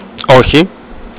*Okh'i*ohiNo
oxi.au